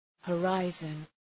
Προφορά
{hə’raızən}